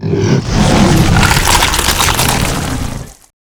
dragonbite.wav